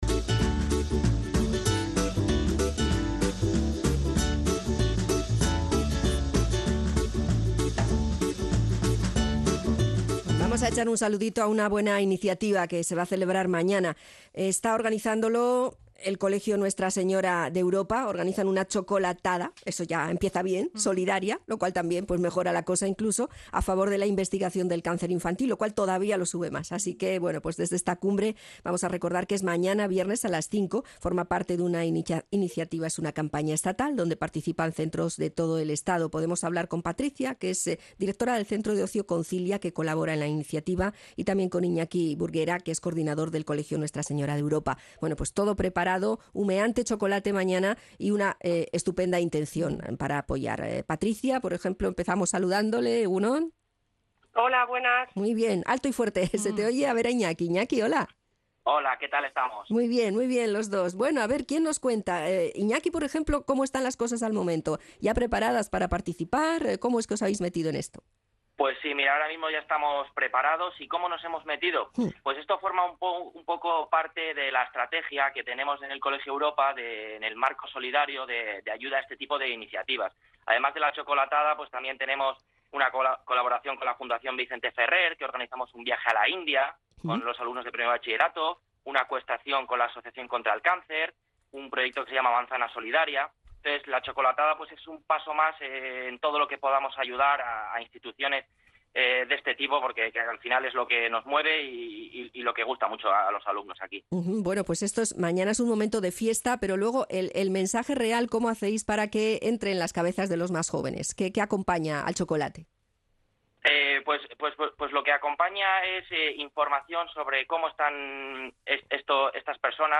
Podcast: Entrevista Herri Irratia, Chocolatada Solidaria | 2020